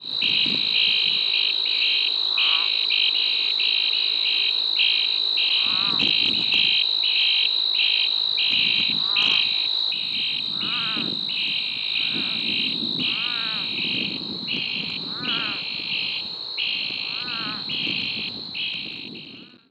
Advertisement Calls
It is produced by a male toad in order to attract females during the breeding season and to warn other rival males of his presence.
The advertisement of the Texas Toad is a short ratcheting or trill, repeated quickly.
Sound  A distant group of toads calling at night in Brewster County, Texas, along with insect sounds and a spadefoot calling.